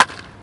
bat+hit+ball.wav